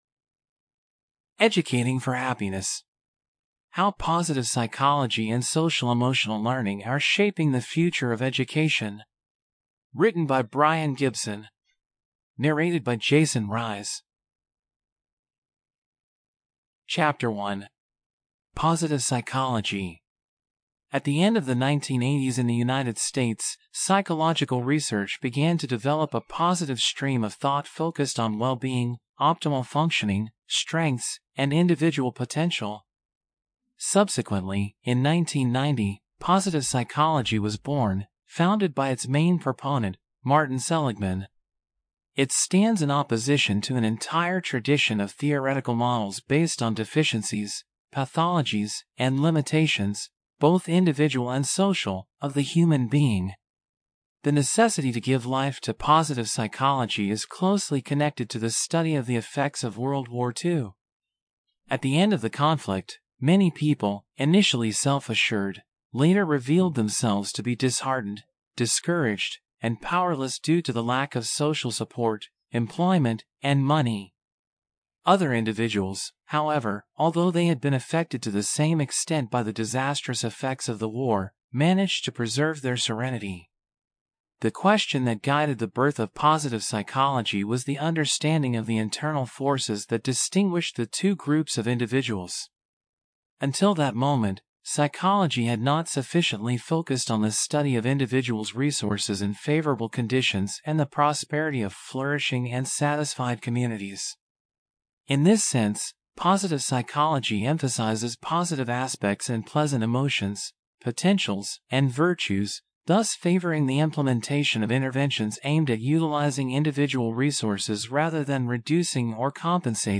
Get Mix of Full Audiobooks in Self Development, Health & Wellness Podcast - Educating for Happiness: How Positive Psychology and Social-Emotional Learning Are Shaping the Future of Education - Brian Gibson | Free Listening on Podbean App